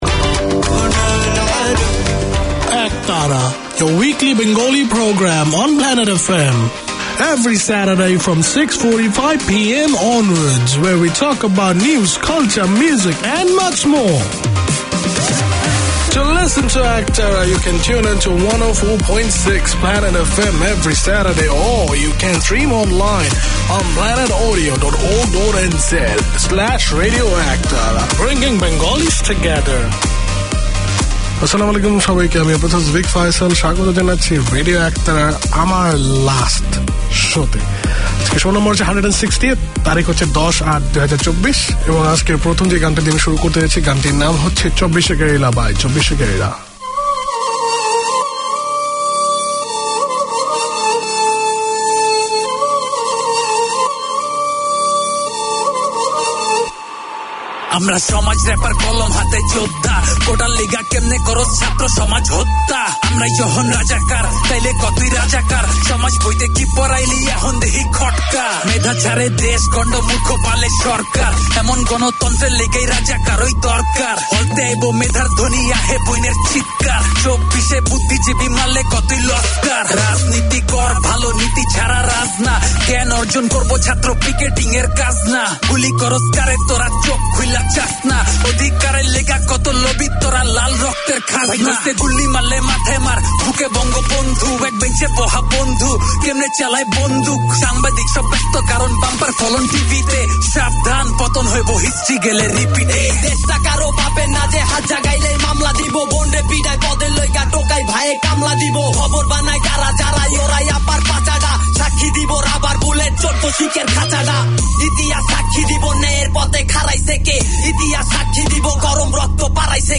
Produced by and for the Bangladeshi community in Auckland, Radio Ektara features current affairs, community notices, music and interviews to entertain and inform.